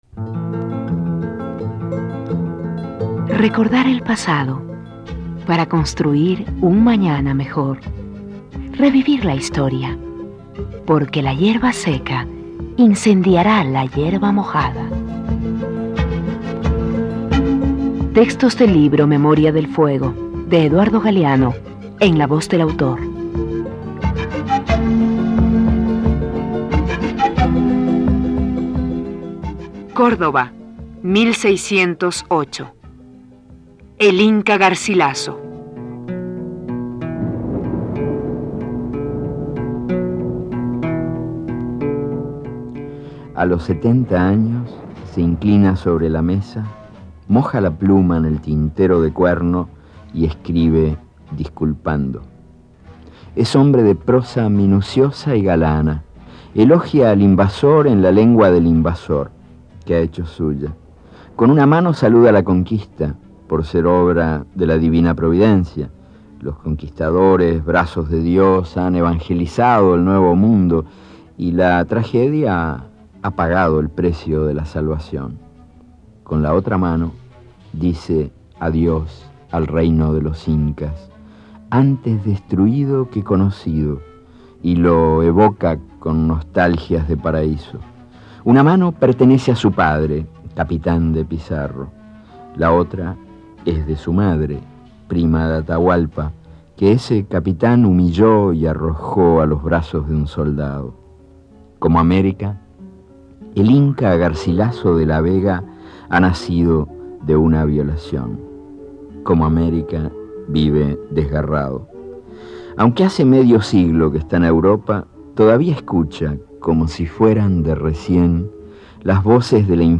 Este archivo de sonido ofrece la lectura del texto en la voz de su autor.
Eduardo Galeano_El Inca Garcilaso.mp3